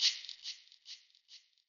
Perc 41.wav